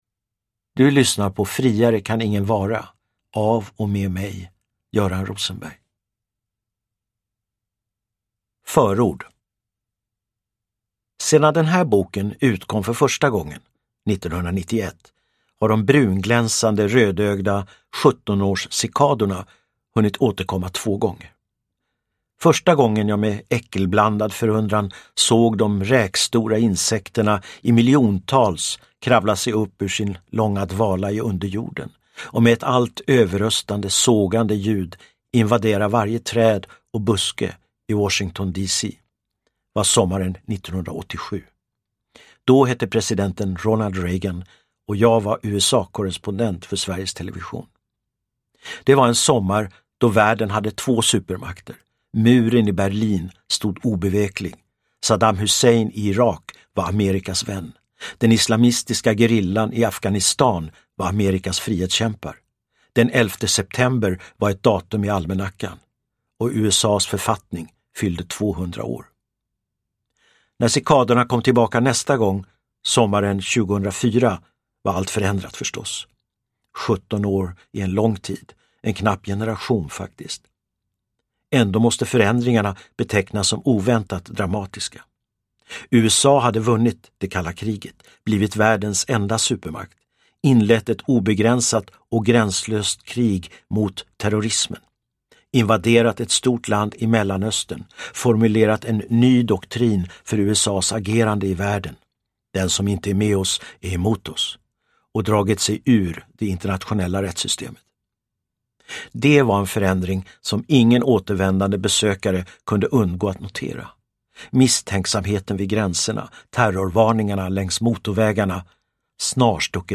Friare kan ingen vara : på spaning efter den amerikanska idén – Ljudbok
Uppläsare: Göran Rosenberg